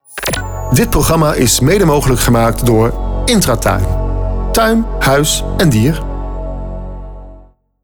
Een aantal voorbeelden van mijn voice-over opdrachten